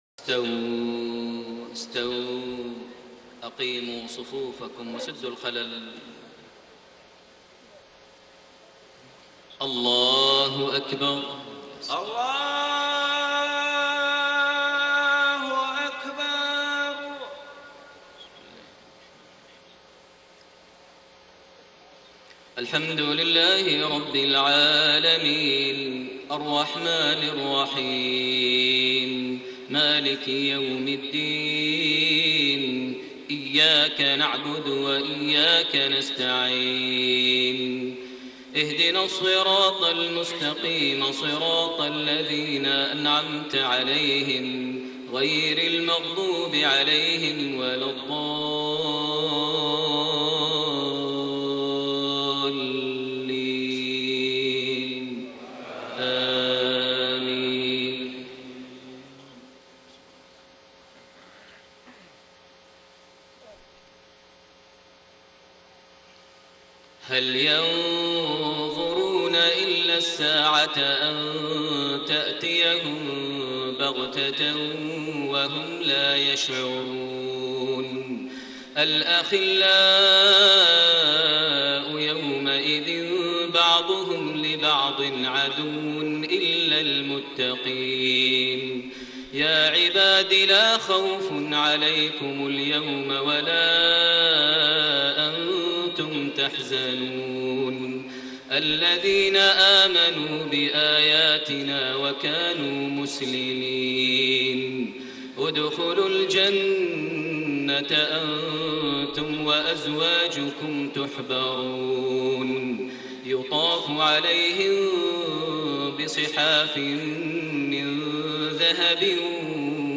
صلاة العشاء 5 شعبان 1433هـ خواتيم سورة الزخرف 66-89 > 1433 هـ > الفروض - تلاوات ماهر المعيقلي